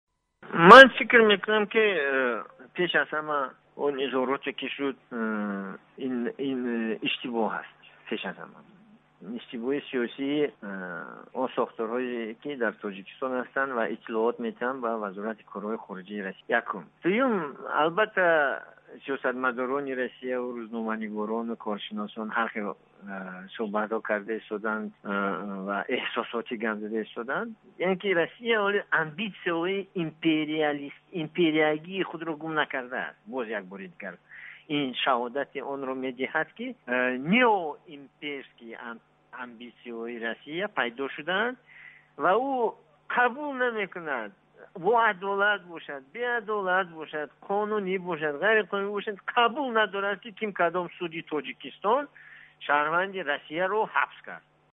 Гуфтугӯ бо Сӯҳроб Шарифов, раиси Маркази таҳқиқоти стратегии назди раёсати ҷумҳури Тоҷикистон